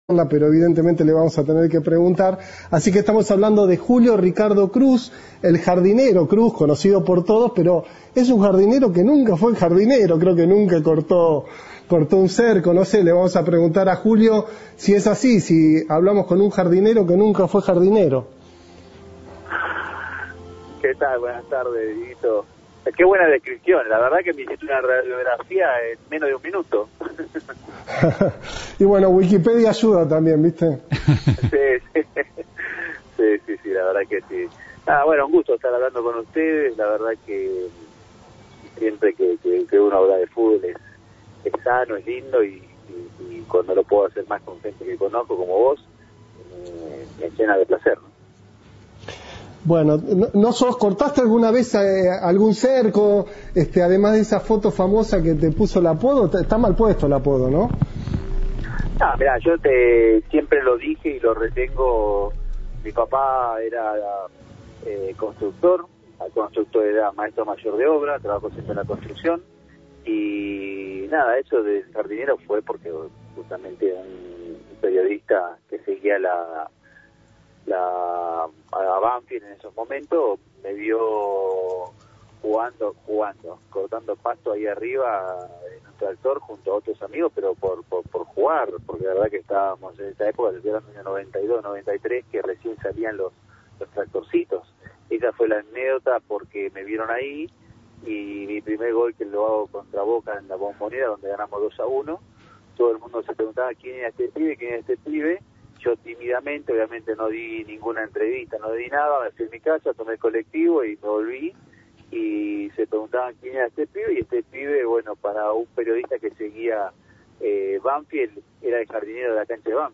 En una entrevista exclusiva con Cadena 3, el ex futbolista habló sobre su carrera en el país y en Europa.